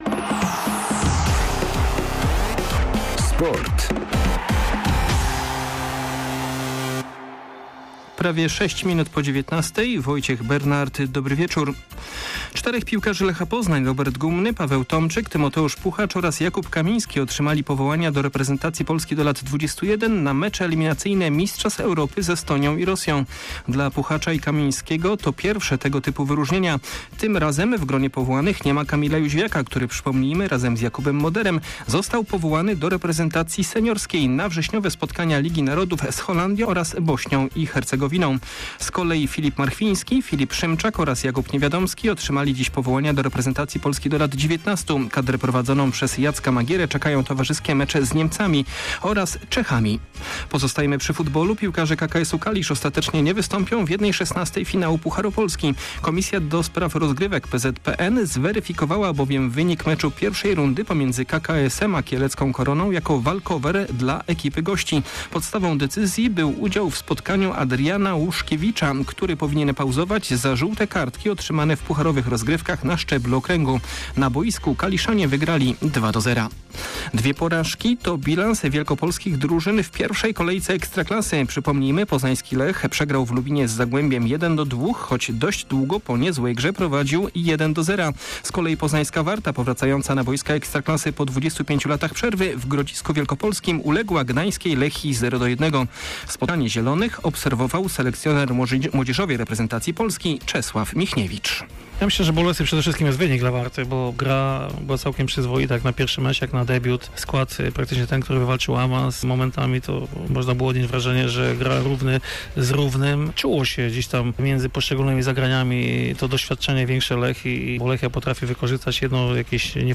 25.08. SERWIS SPORTOWY GODZ. 19:05